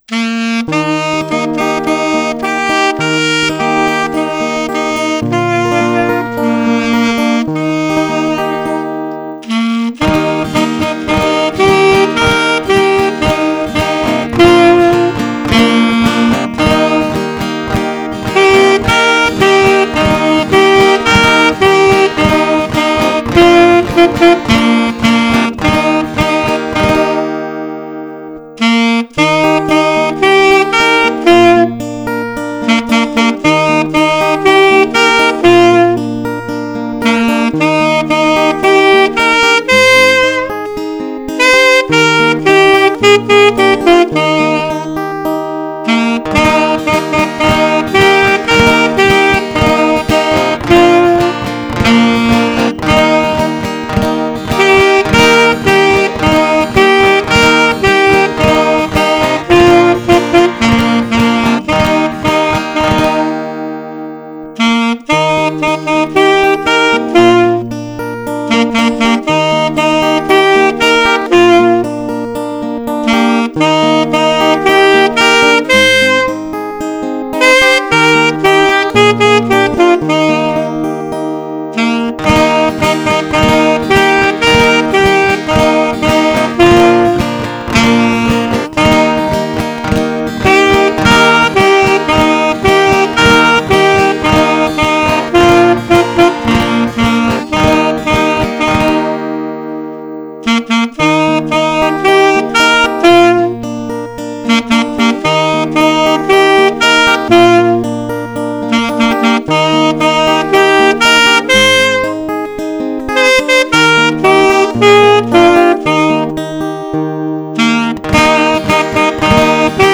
instrumental
verkehrssong-instrumental.mp3